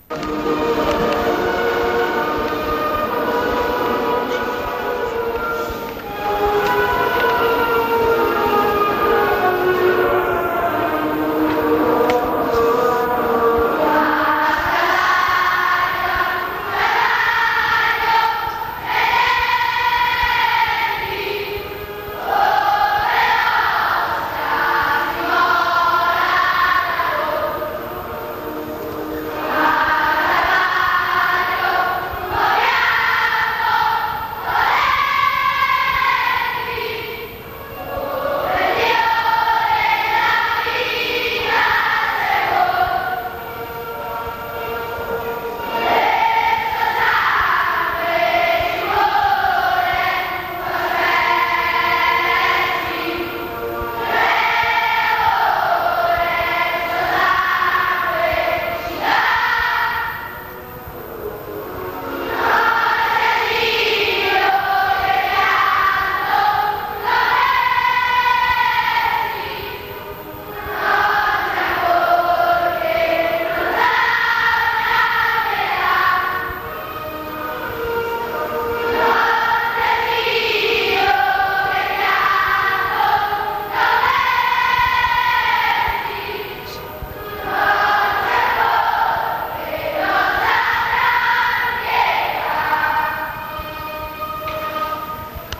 Ovviamente le registrazioni sono a livello amatoriale, per lo più registrate "live" sulle strade, se qualcuno fosse in possesso di registrazioni migliori può, se lo desidera, inviarcele in modo che esse siano messe a disposizione di tutti.